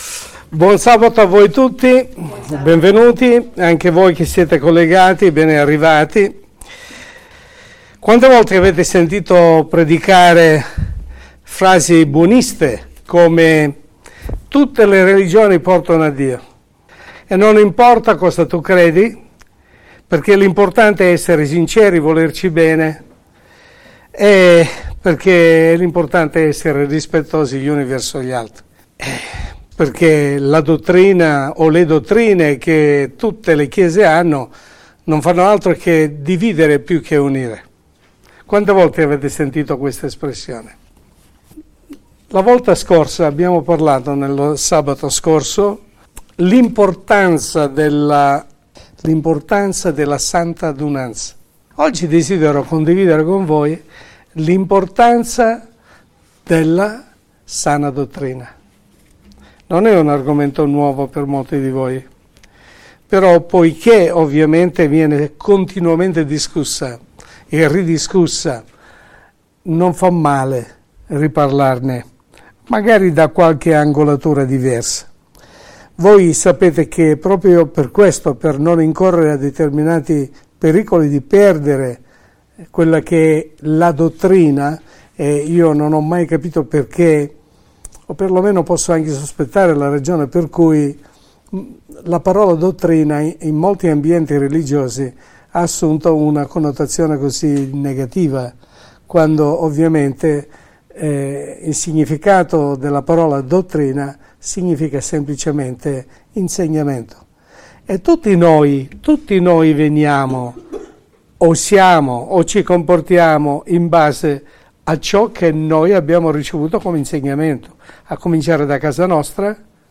Che cos’è più indispensabile l’Unità o la Verità? – Sermone pastorale